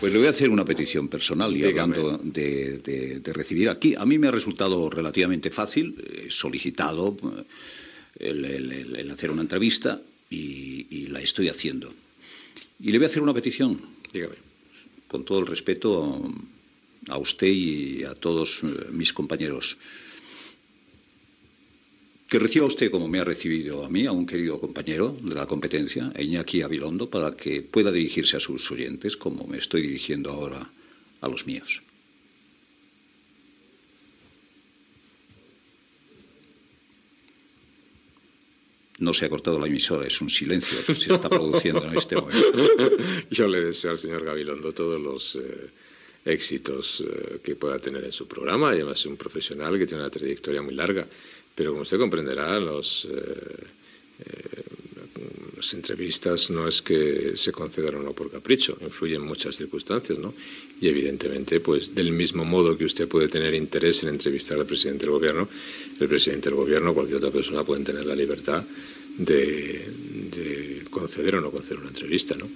Fragment d'una entrevista al president del Govern espanyol José María Aznar on Luis del Olmo li demana que concedeixi una entrevista a Iñaki Gabilondo de la Cadena SER.
Info-entreteniment